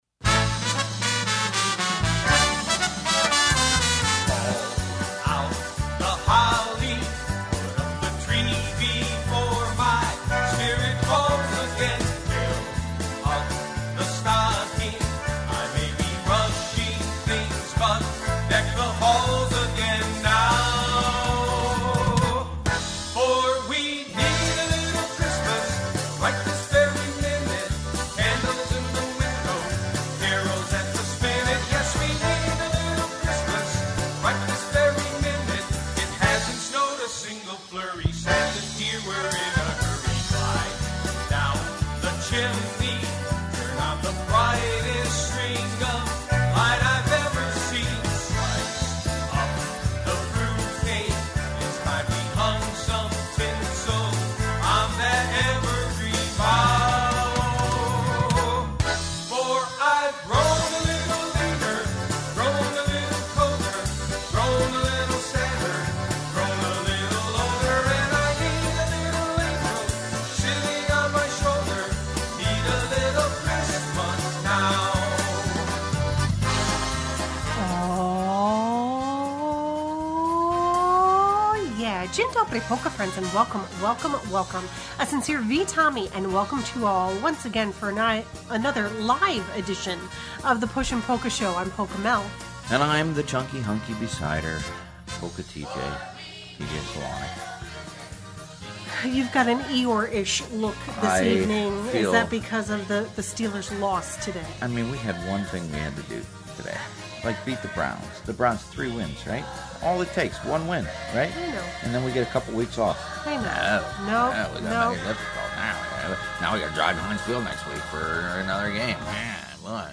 Polkas